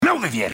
Play, download and share ZERK DEFLECT original sound button!!!!
for-honor-zerk-deflect.mp3